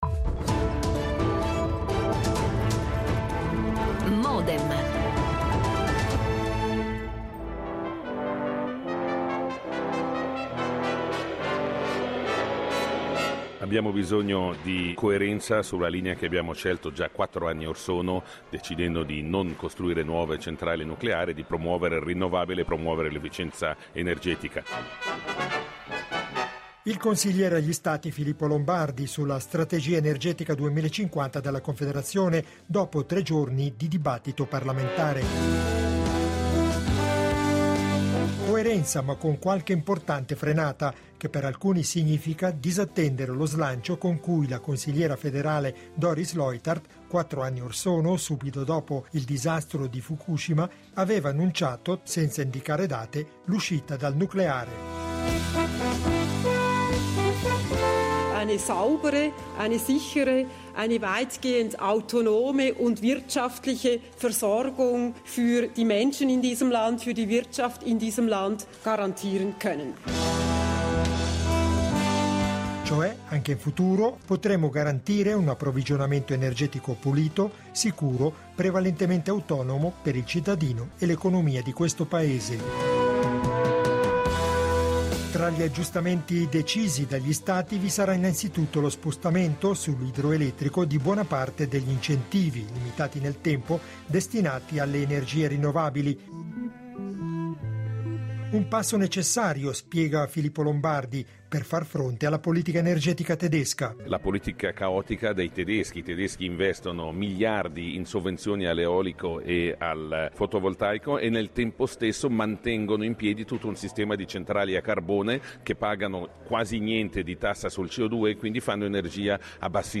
Come traghettare dunque senza traumi la Svizzera nell’era post-atomica? Nel dibattito dalla Sala dei passi perduti di Palazzo federale intervengono: Fabio Abate , Cons. agli Stati PLR Oskar Freysinger , Cons. naz. UDC Silva Semadeni , Cons. naz. PS Christian Van Singer , Cons. Naz. Verdi
L'attualità approfondita, in diretta, tutte le mattine, da lunedì a venerdì